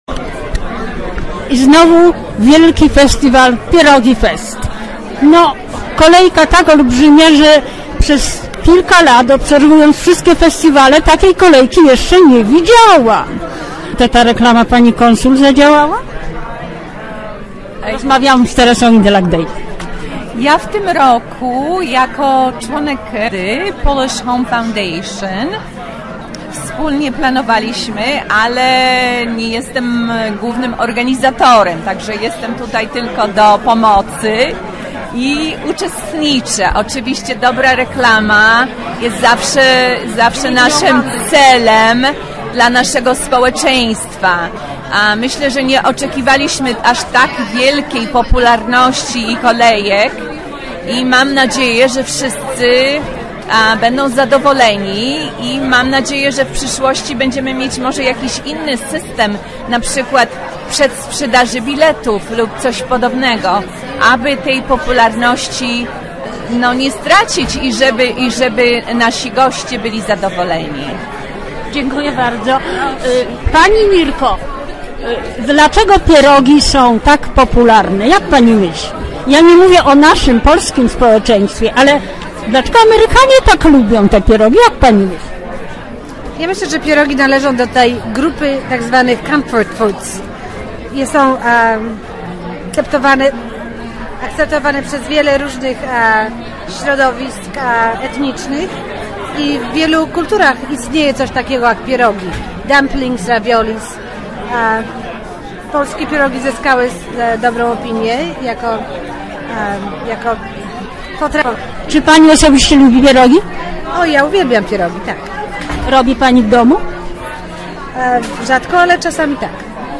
Zapraszamy do wysłuchania zapisanych rozmów z organizatorami, konsumentami i gośćmi festiwalu. Z rozmów tych wynika jasno, że Festiwal był dużym hitem.